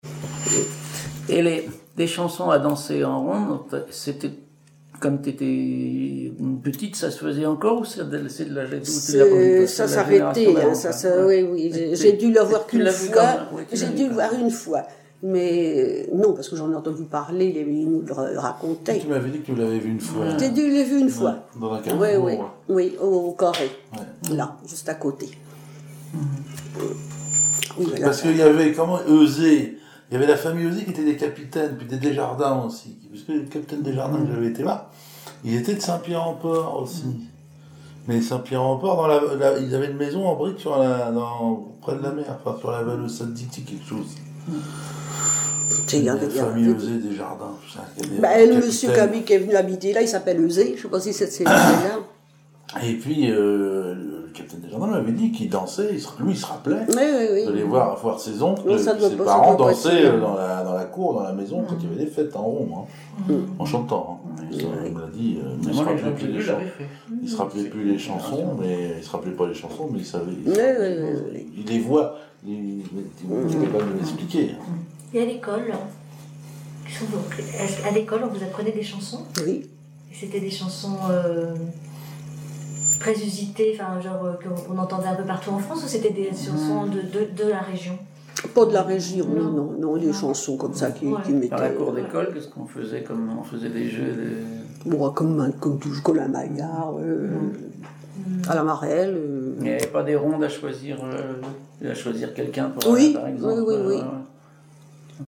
Les chansons à danser en ronde
Catégorie Témoignage